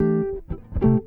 gtr_84.wav